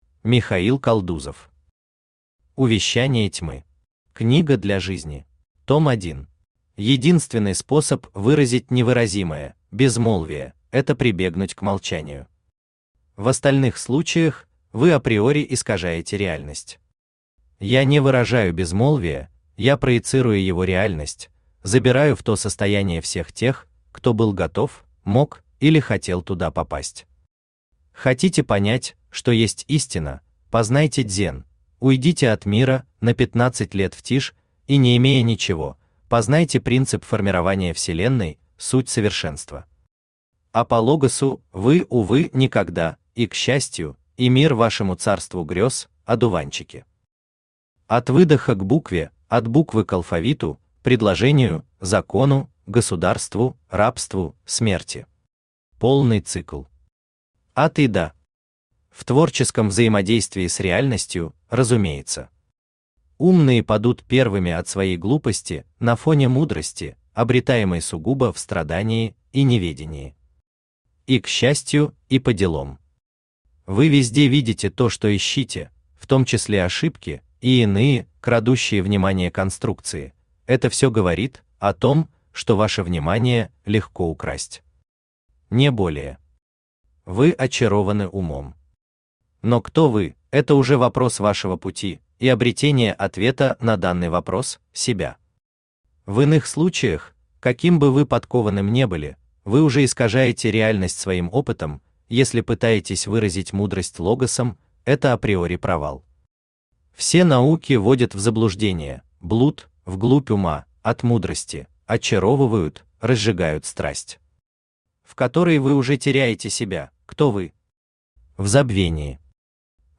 Аудиокнига Увещание тьмы. Книга для жизни | Библиотека аудиокниг
Книга для жизни Автор Михаил Константинович Калдузов Читает аудиокнигу Авточтец ЛитРес.